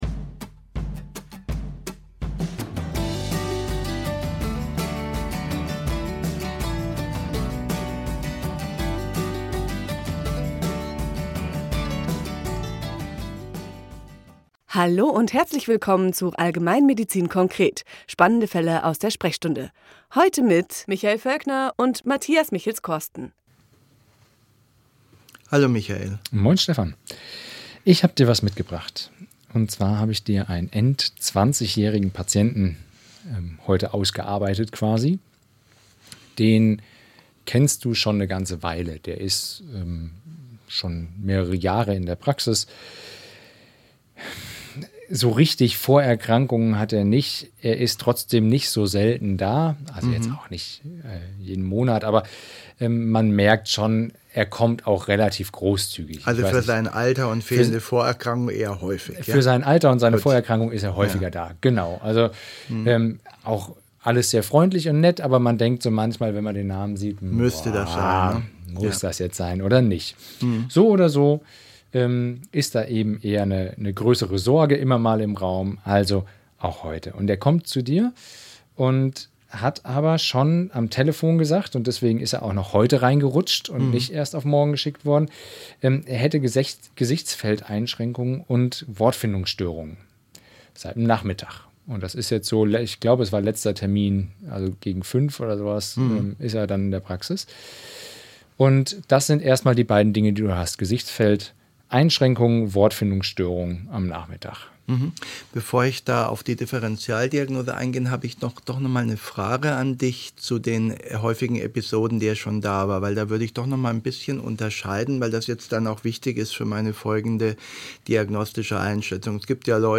Gemeinsam erläutern die beiden ihre Überlegungen zu möglichen Differentialdiagnosen und dem weiteren Procedere.